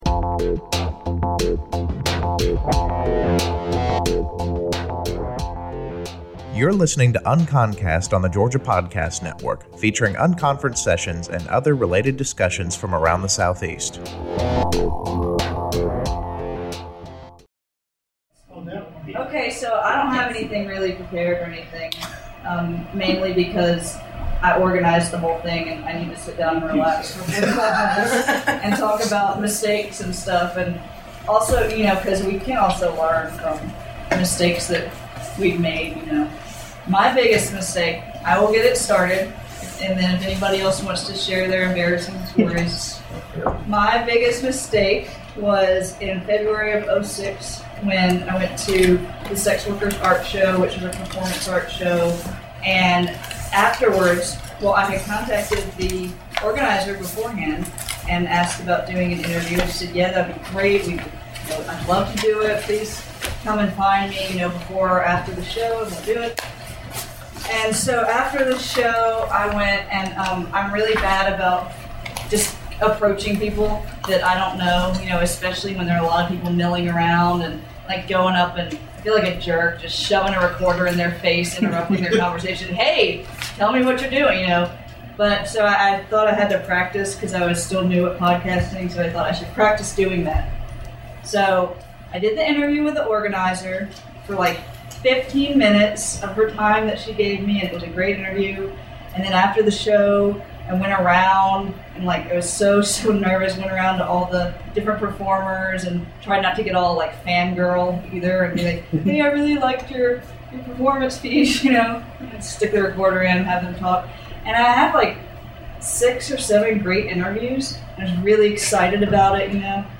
PodCamp Atlanta took place March 17 and 18 at Emory University.
Featuring unconference sessions and other related discussions from around the Southeast.